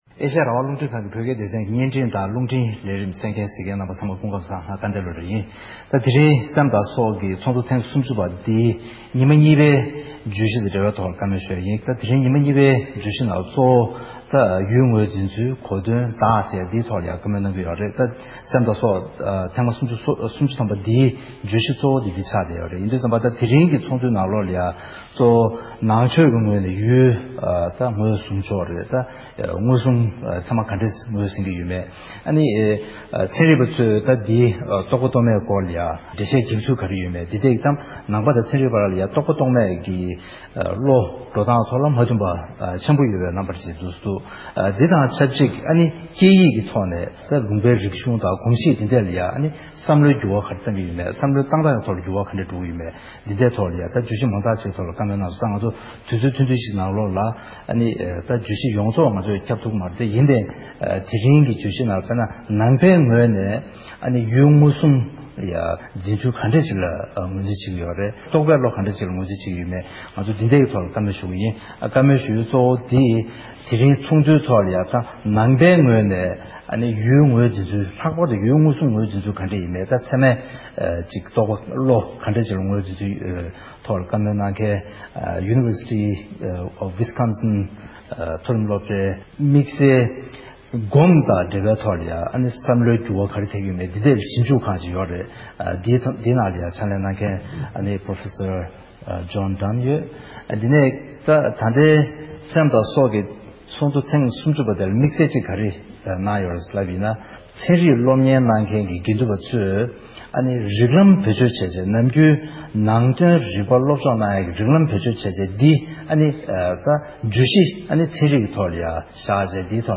བགྲོ་གླེང་།
གནས་ཚུལ་ཕྱོགས་སྒྲིག